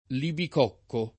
vai all'elenco alfabetico delle voci ingrandisci il carattere 100% rimpicciolisci il carattere stampa invia tramite posta elettronica codividi su Facebook Libicocco [ libik 0 kko ] pers. m. — uno dei diavoli dell’Inferno dantesco